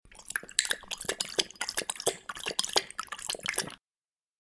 Cat Drinking 7 Fx Sound Button - Free Download & Play